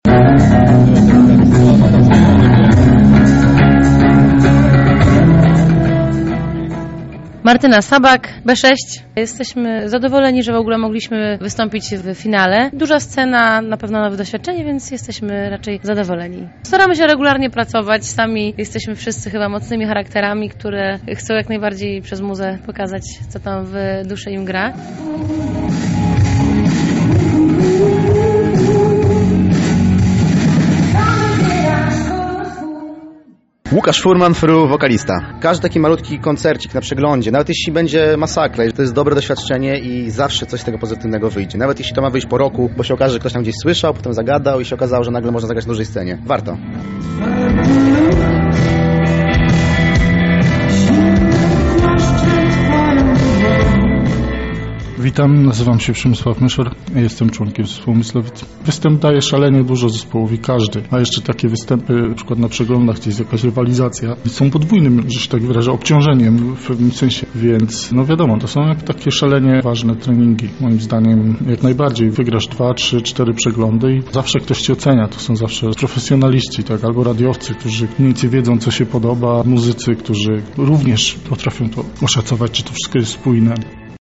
RAF 2018 – relacja dźwiękowa